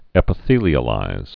(ĕpə-thēlē-ə-līz) or ep·i·the·lize (-thēlīz)